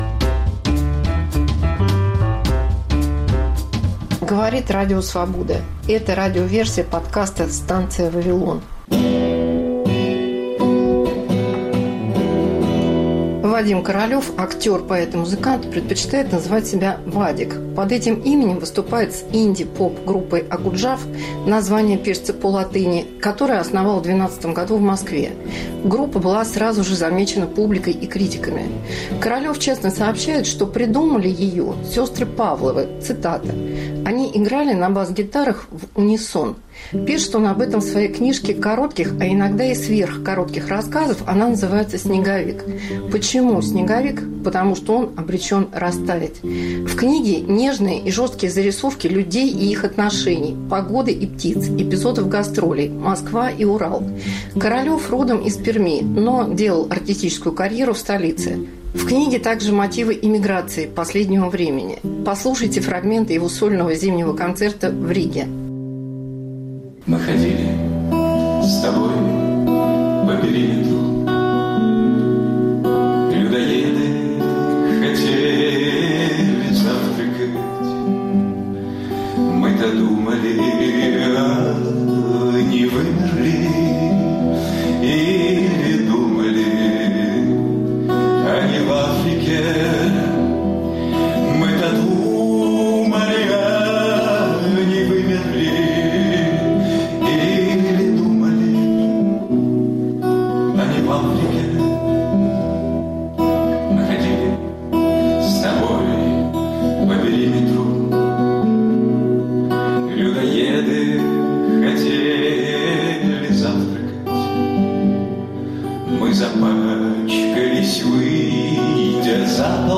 Новогодний концерт